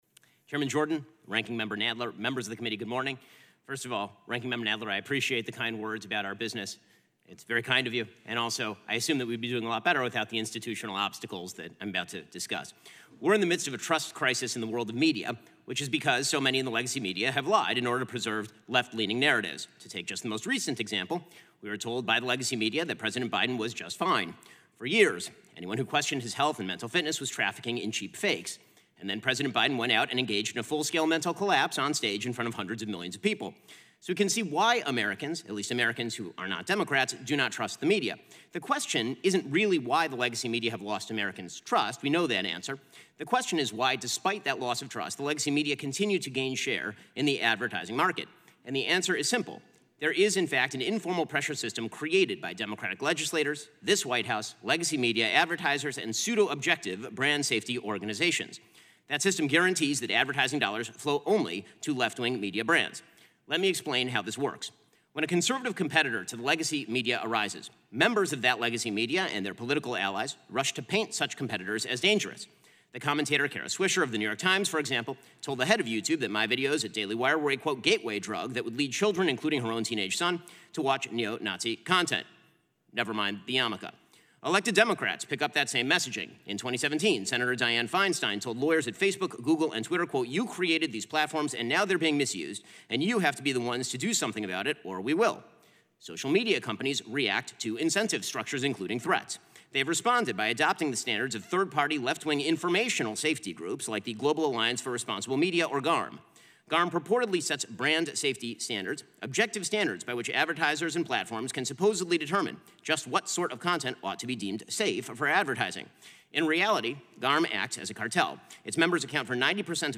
Opening Remarks at a House Judiciary Committee Hearing on Brand Control of Online Speech
delivered 9 July 2024, Washington, D.C.